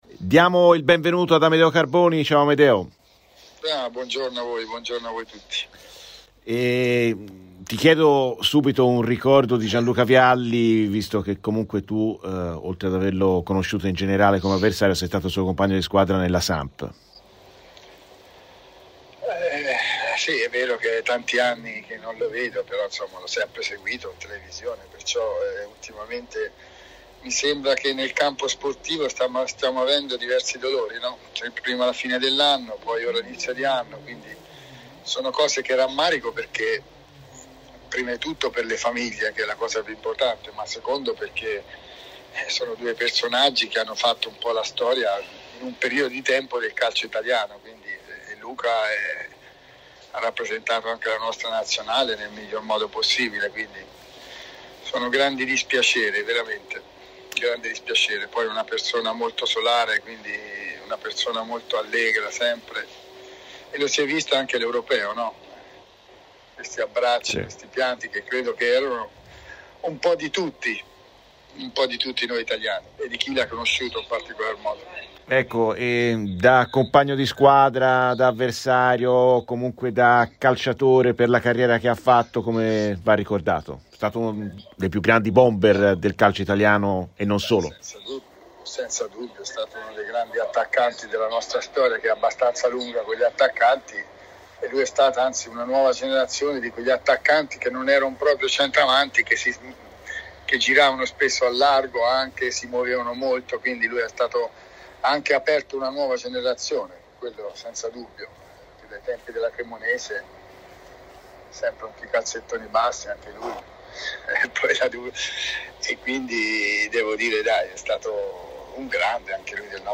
Amedeo Carboni, dirigente sportivo ed ex giocatore tra le altre di Roma e Valencia, ha parlato ai microfoni di RadioFirenzeViola nel corso della trasmissione "Viola amore mio".